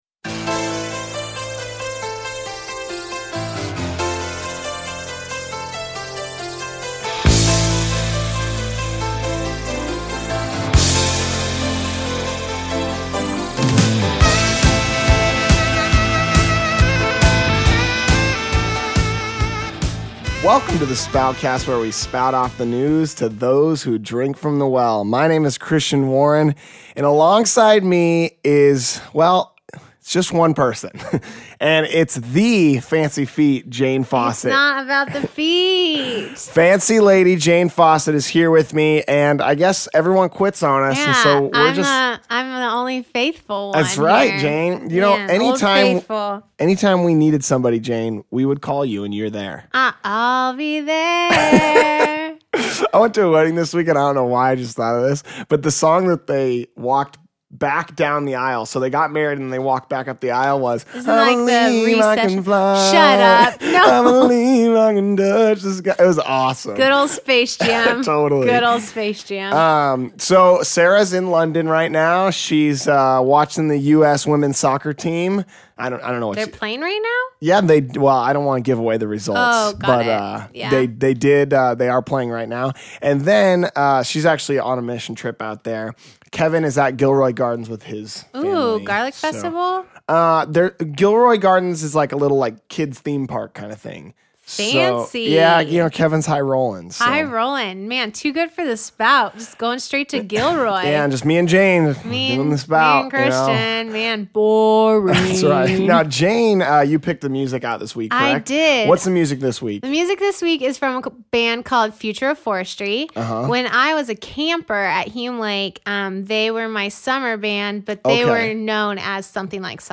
This week's interview